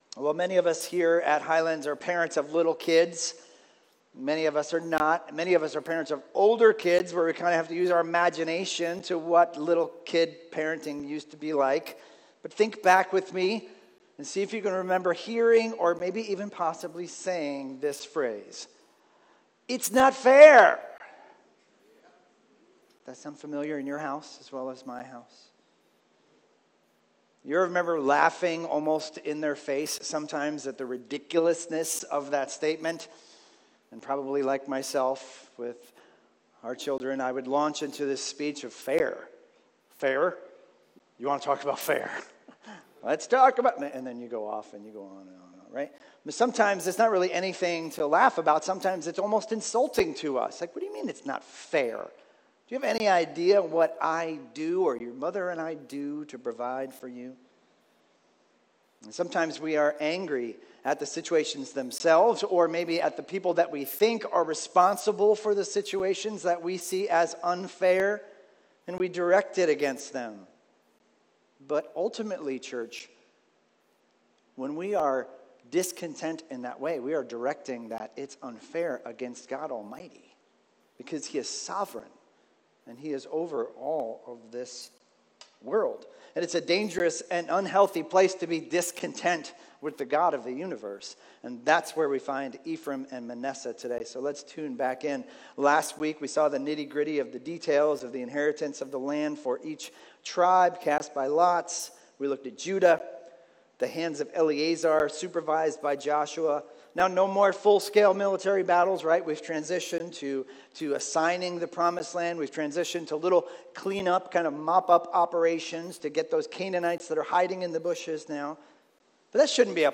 Sermons – Highlands Bible Church
Expositional teaching series thru the book of Joshua.